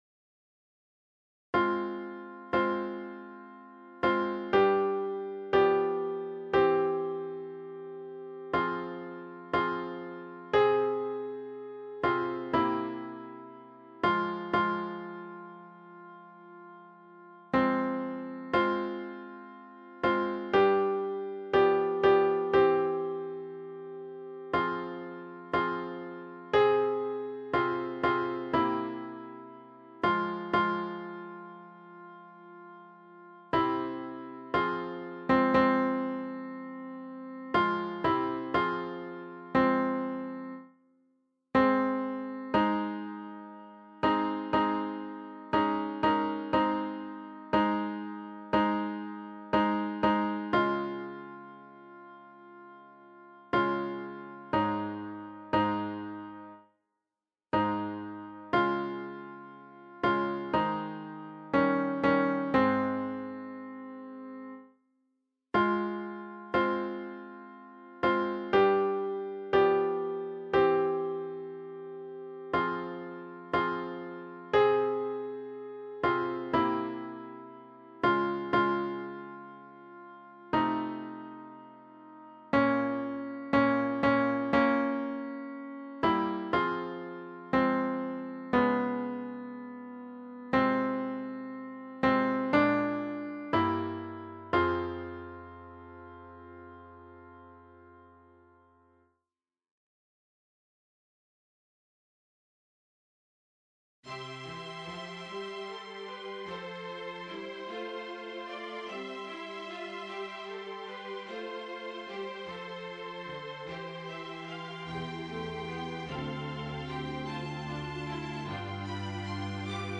ConcertdeNoelMezzo.mp3